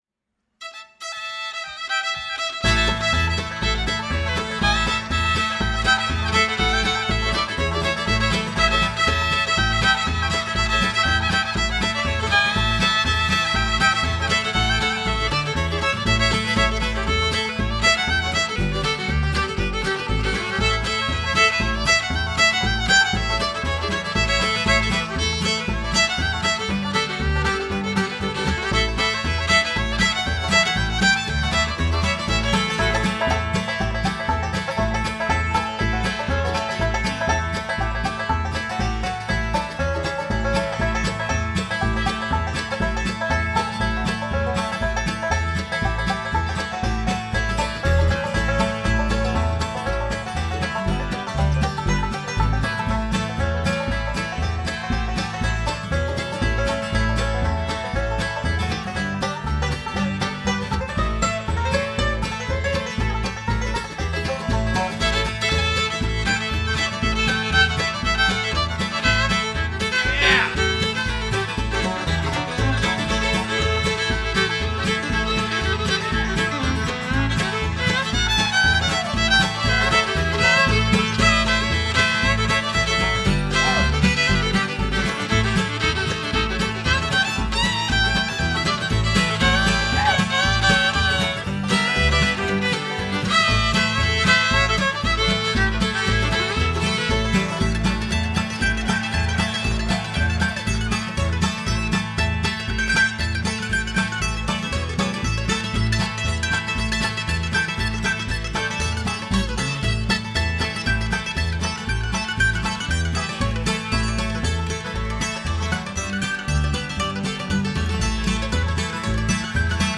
Tileworks of Bucks County Doylestown, PA
Mandolin
Guitar
Banjo
Fiddle/Vocals
Bass/Vocals